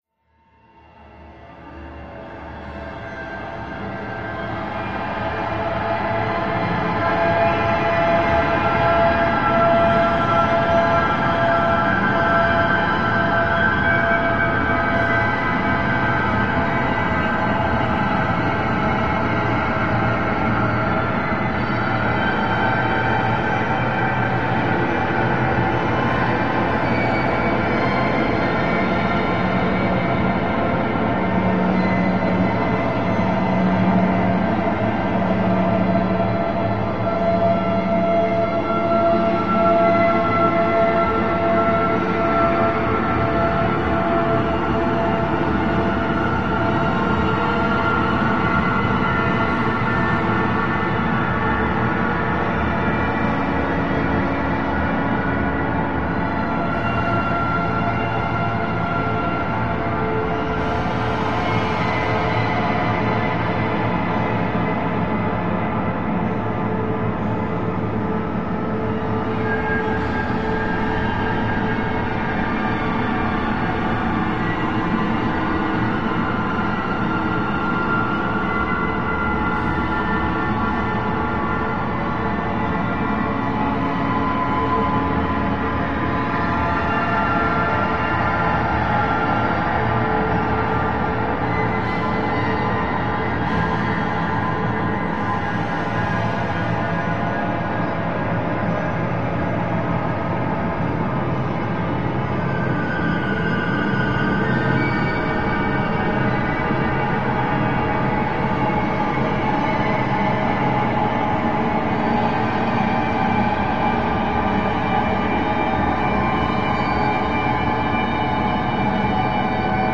Activity Orchestra Atonal Metallic Movement